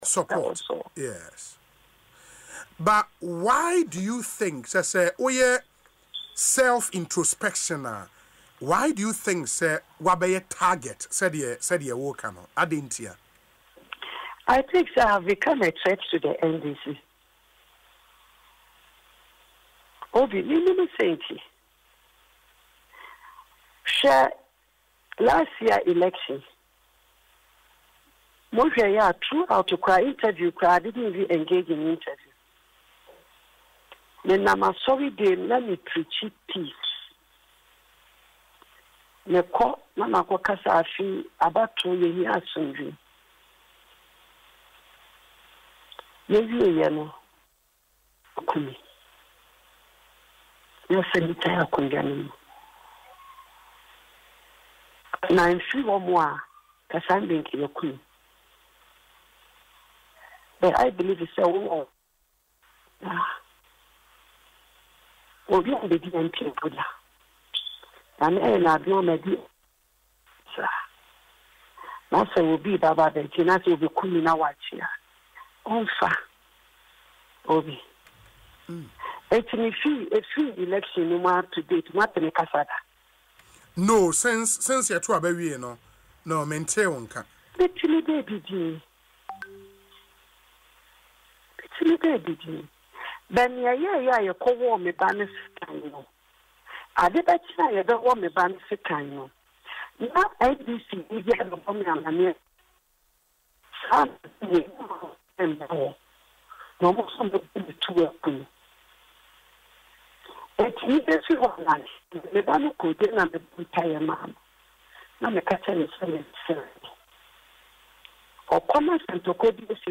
Speaking on Asempa FM’s Ekosii Sen show, the former Fisheries and Aquaculture Minister recounted how she was allegedly attacked by a group of unidentified men, including one armed with a knife.